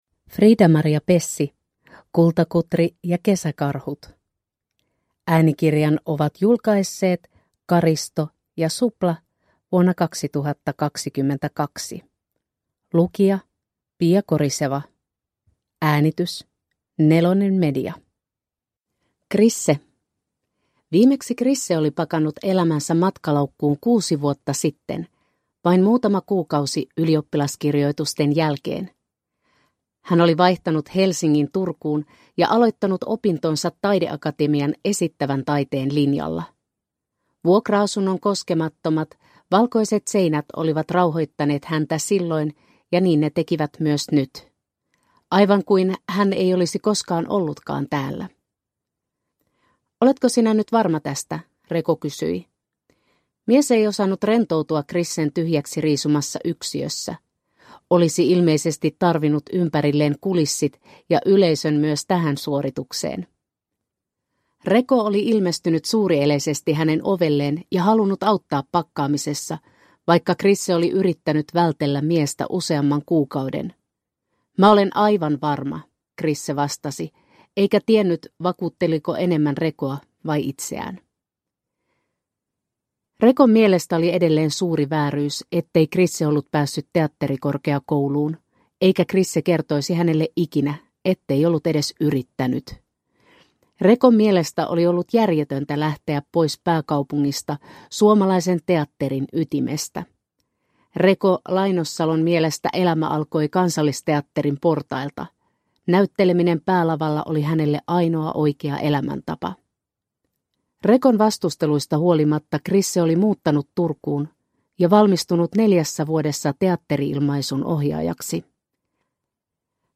Kultakutri ja kesäkarhut – Ljudbok – Laddas ner